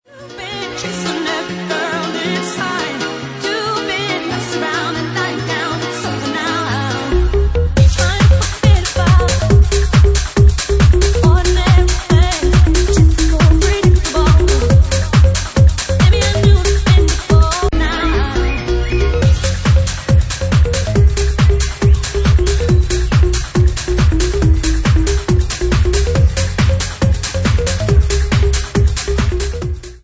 Genre:Bassline House
140 bpm